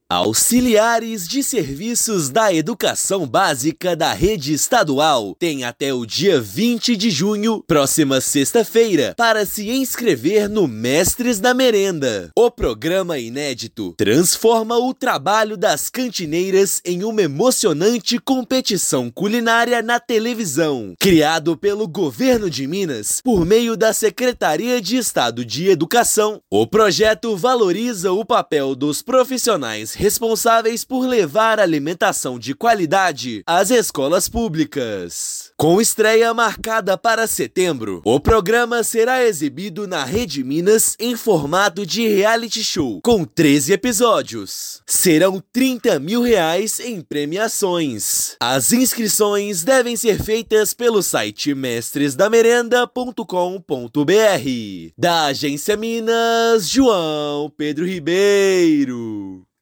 Competição culinária vai premiar receitas escolares e exibir histórias inspiradoras na TV pública mineira. Ouça matéria de rádio.